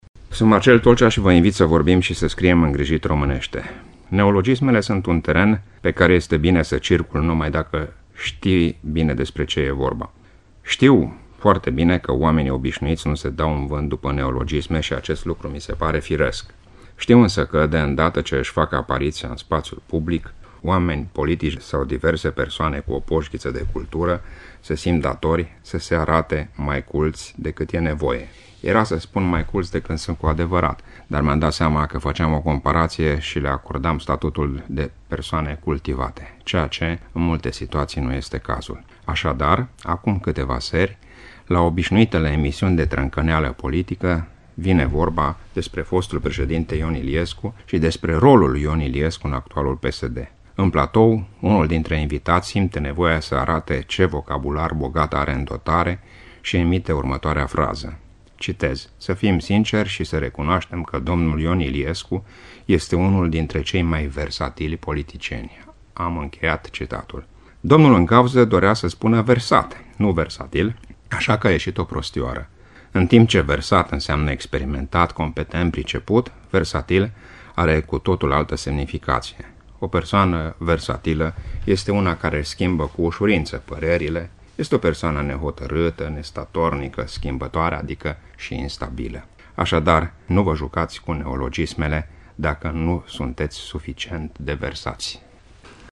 (rubrică difuzată în 11 noiembrie 2015)